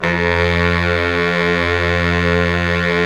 SAX 2 BARI0I.wav